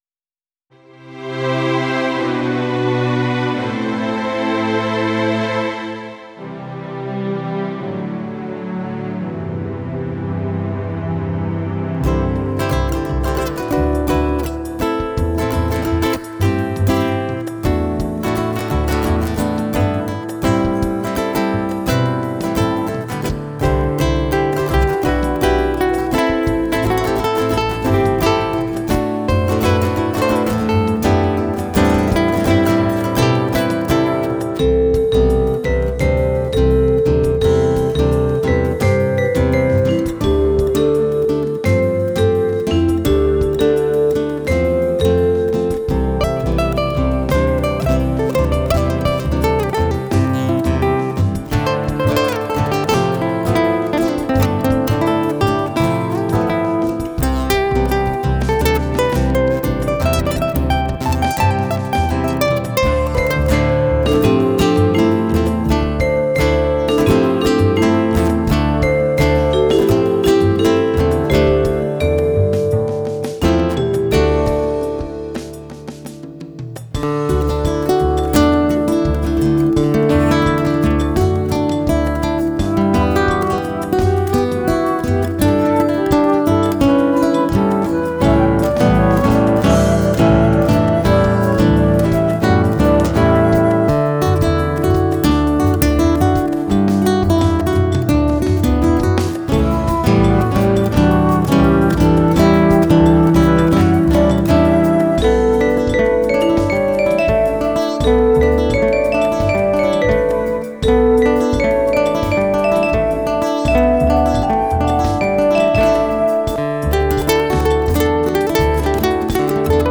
Akustische Musik